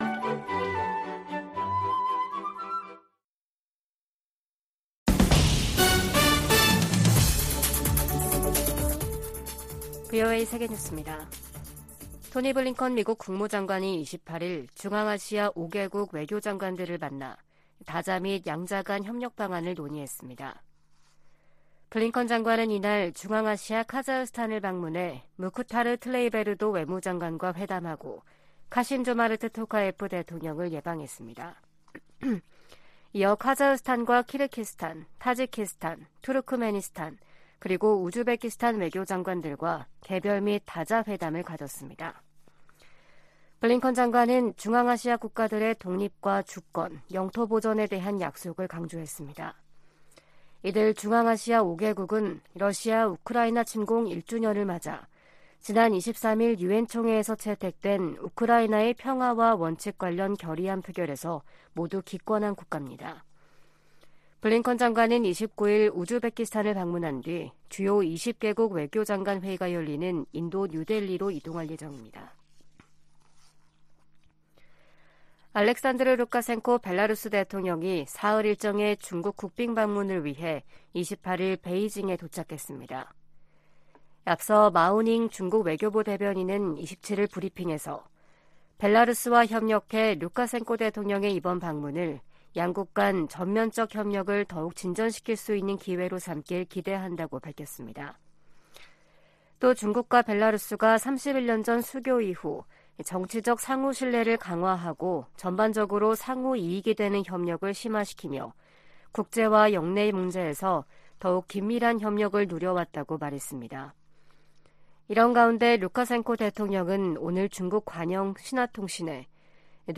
VOA 한국어 아침 뉴스 프로그램 '워싱턴 뉴스 광장' 2023년 3월 1일 방송입니다. 미국 정부는 북한에 도발을 중단하고 대화에 나설 것을 거듭 촉구하며 외교가 여전히 해법이라고 밝혔습니다. 미 국무부 군축·국제안보 차관은 북한을 ‘무시할 수 없는 도전’으로 규정하면서 핵과 미사일 개발에 우려를 나타냈습니다. 미국의 확장억제 신뢰를 높이고 한국의 자체 핵무장론을 진화하기 위해 나토형 핵계획그룹에 준하는 협의체를 세우자는 의견이 나오고 있습니다.